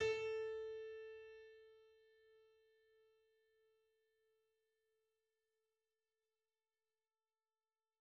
A440.wav